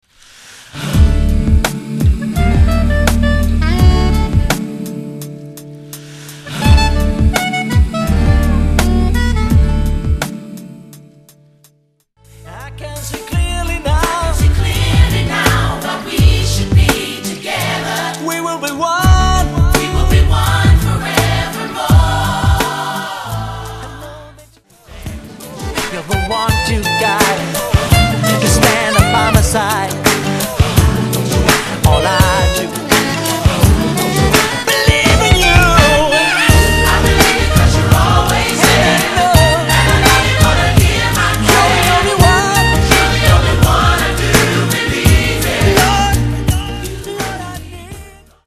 Club-Mix*